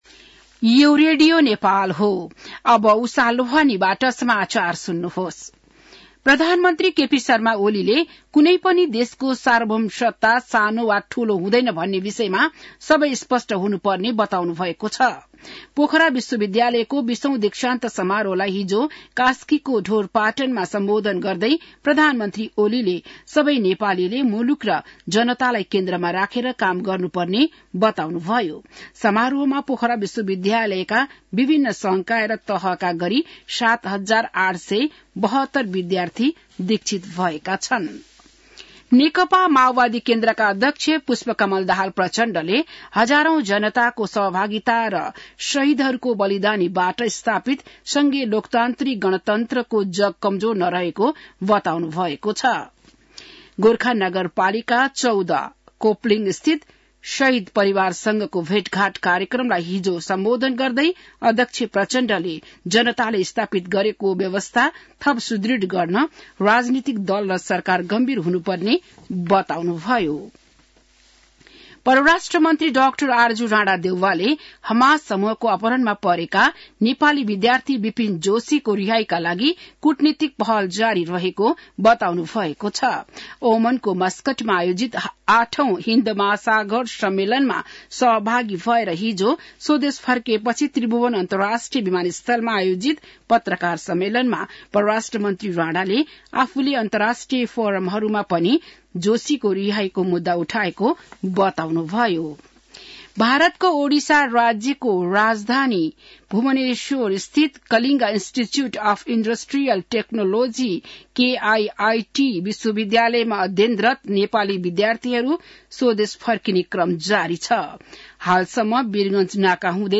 An online outlet of Nepal's national radio broadcaster
बिहान १० बजेको नेपाली समाचार : १० फागुन , २०८१